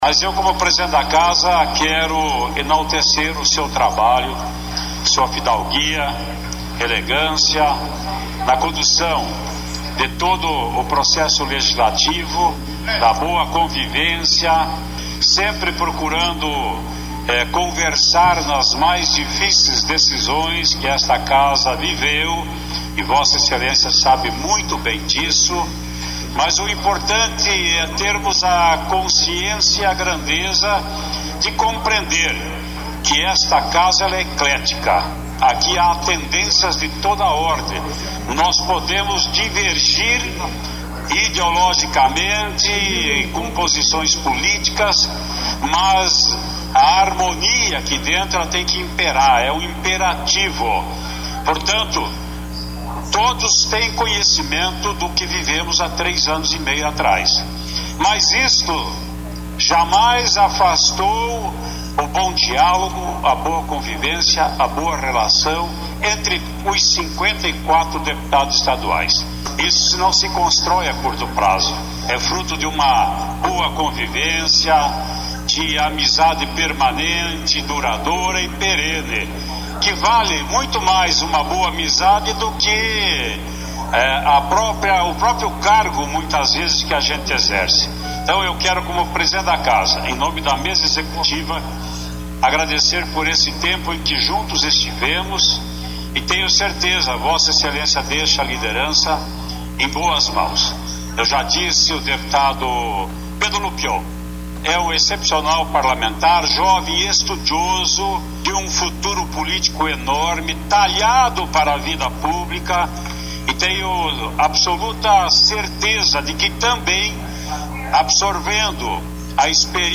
Em discurso, Traiano exalta trabalho de Romanelli e dá boas vindas a Pedro Lupion
Ouça trecho do discurso do presidente da Assembleia Legislativa, onde ela exalta o trabalho de Romanelli e dá boas vindas a Pedro Lupion , novo líder do Governo na Casa.